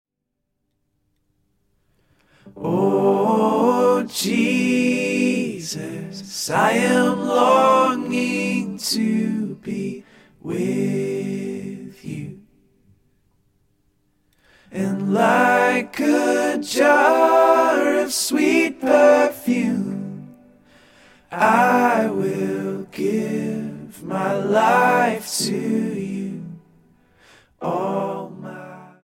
STYLE: Ambient/Meditational
is in a suitably sombre mood
just voice and keys